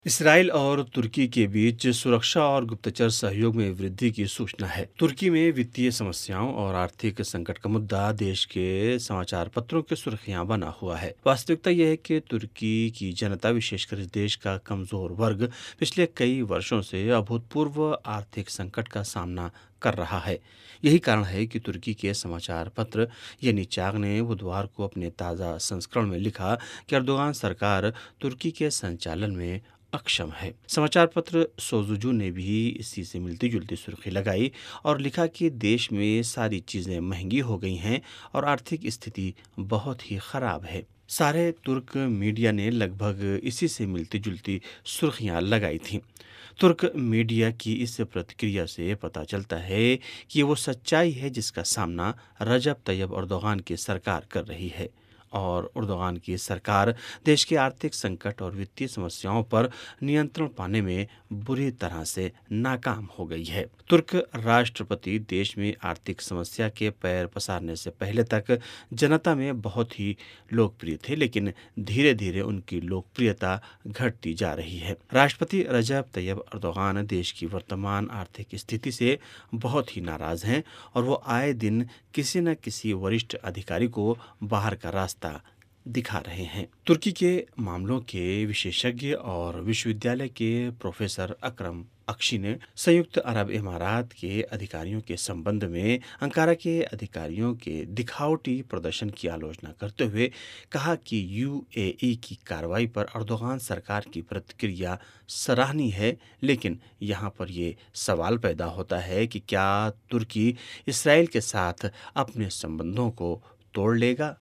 तुर्की और इस्राईल में बढ़ा रक्षा सहयोग...रिपोर्ट